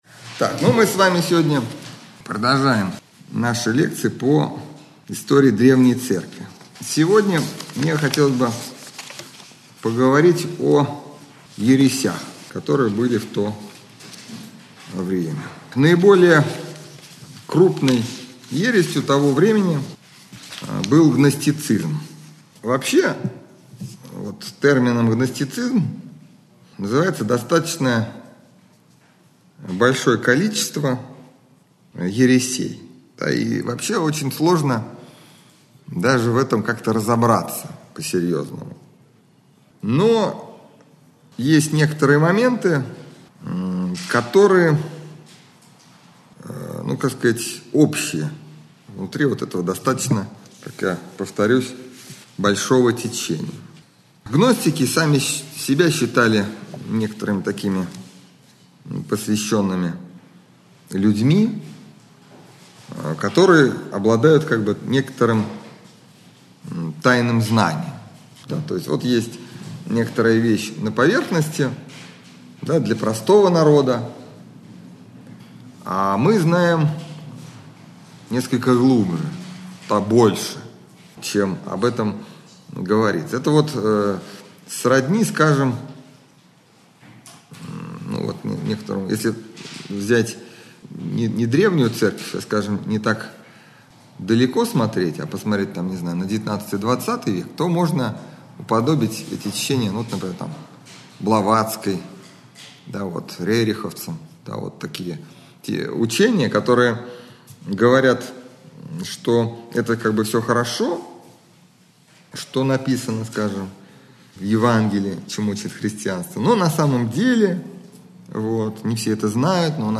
Общедоступный православный лекторий 2013-2014